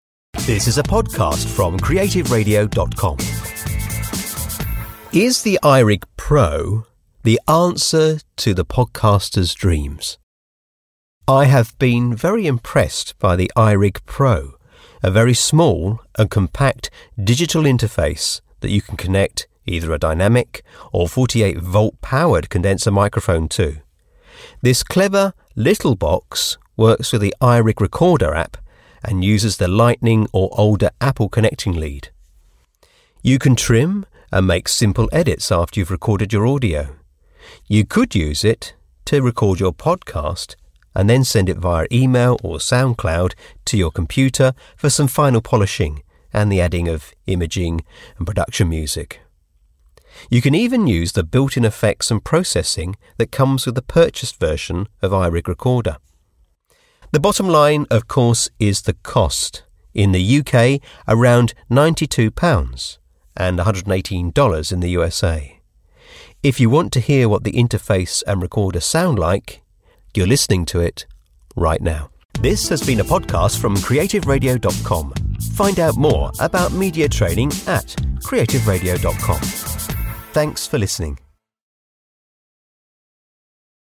If you want to hear what the interface and recorder sound like, download the AudioBoom Podcast here.